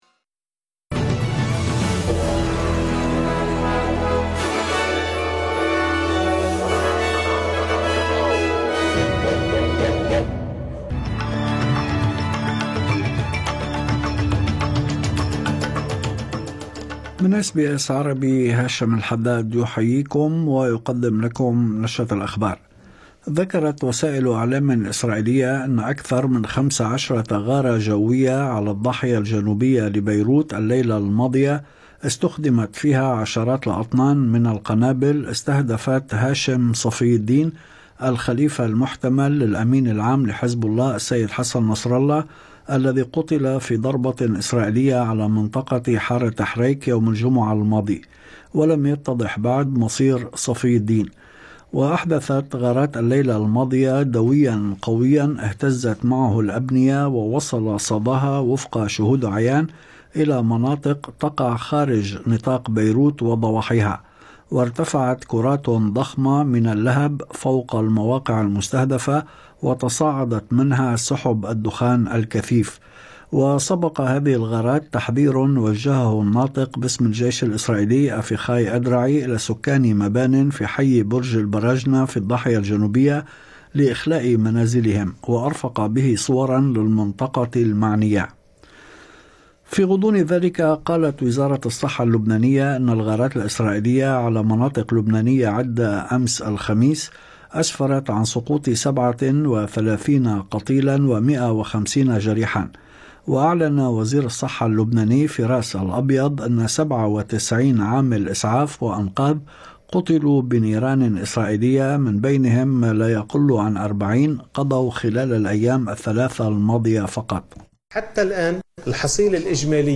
نشرة أخبار الظهيرة 4/10/2024